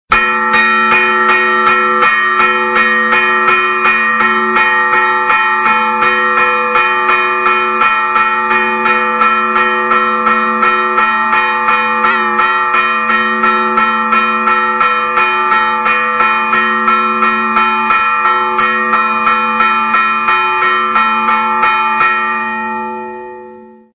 A continuació podeu escoltar uns quants "tocs" de campanes:
Foc